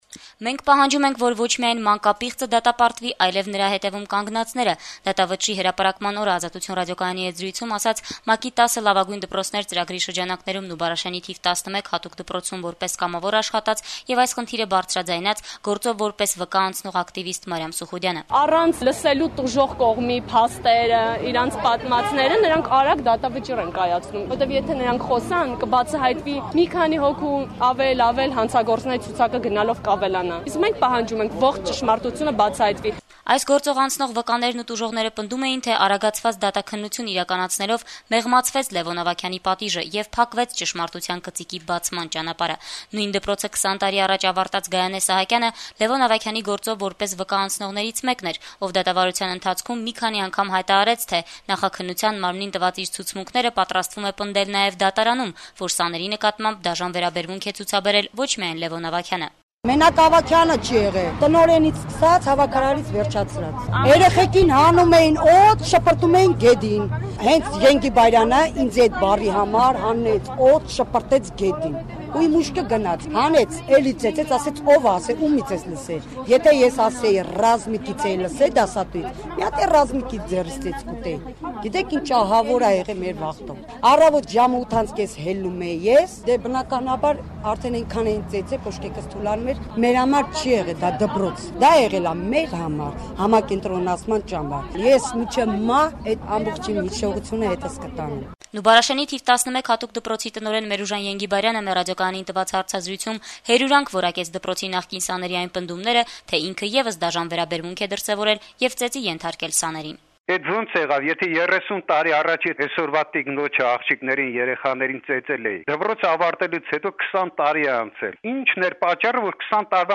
«Ազատություն» ռադիոկայանին տված հարցազրույցում հերյուրանք որակեց դպրոցի նախկին սաների այն պնդումները